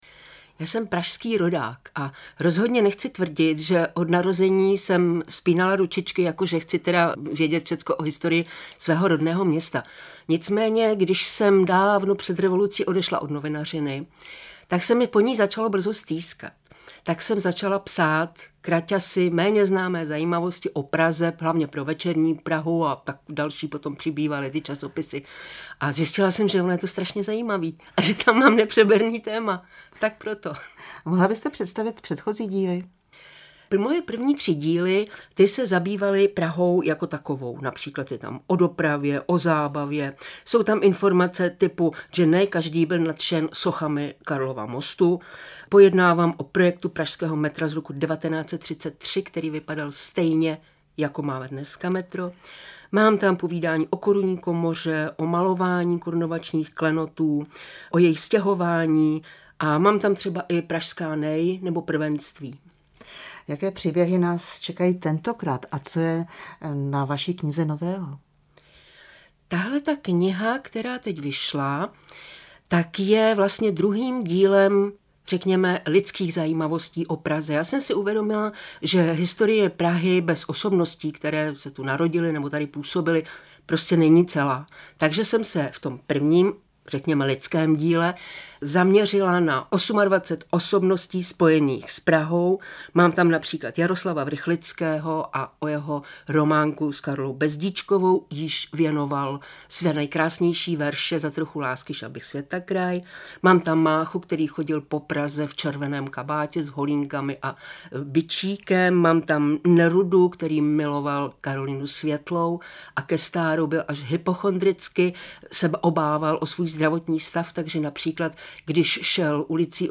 na stanici Vltava